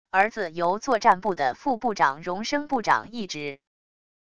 儿子由作战部的副部长荣升部长一职wav音频生成系统WAV Audio Player